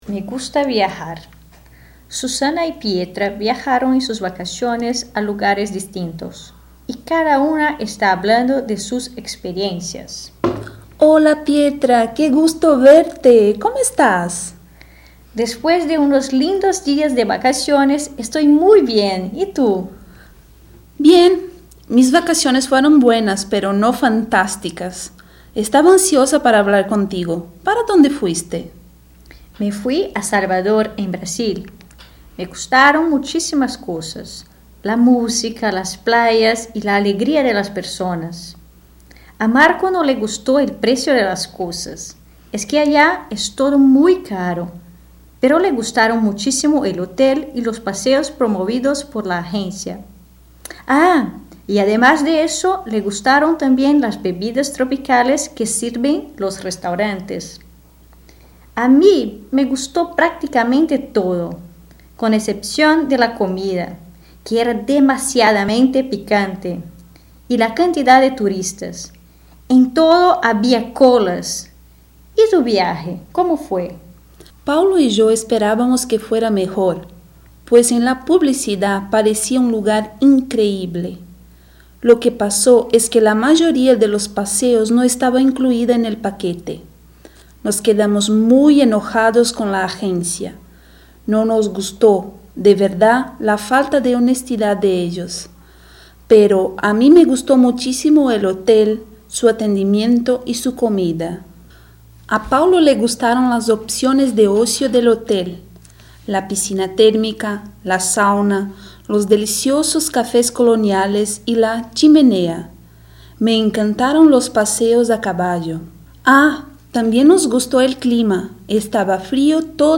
Diálogo XXI: O gosto de viajar